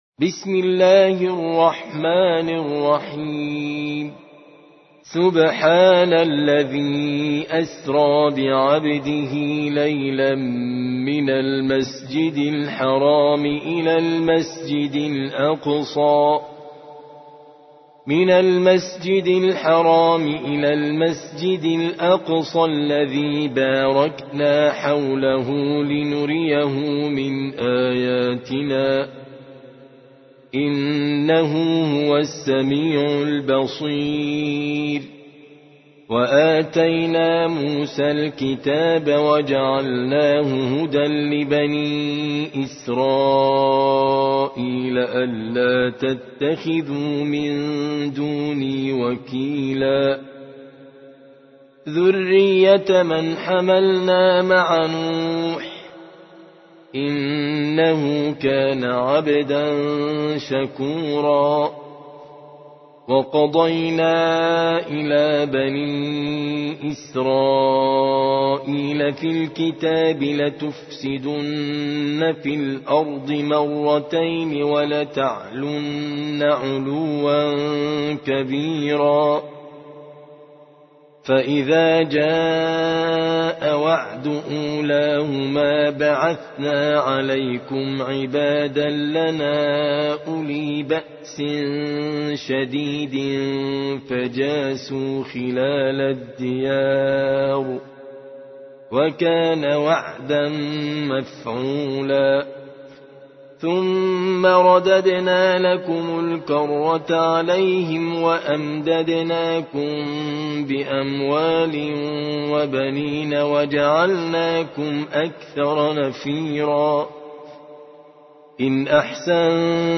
17. سورة الإسراء / القارئ